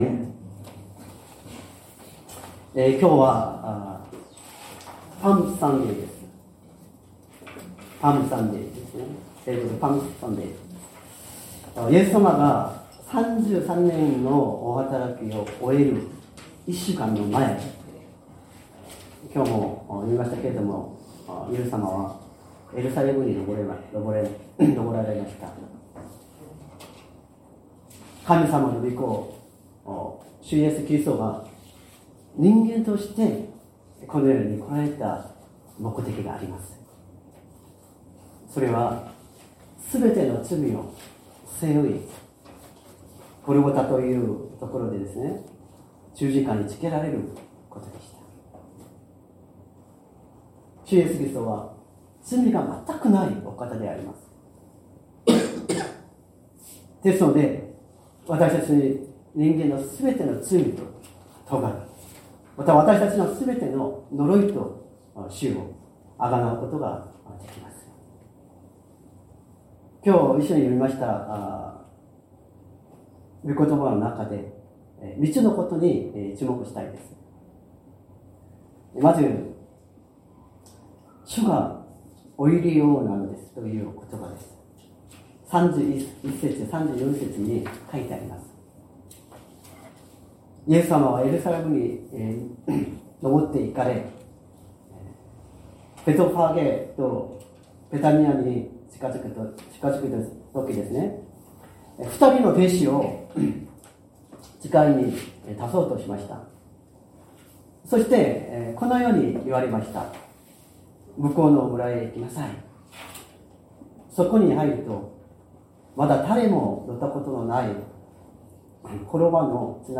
説教アーカイブ 2025年04月13日朝の礼拝「エルサレムに迎えられる」
音声ファイル 礼拝説教を録音した音声ファイルを公開しています。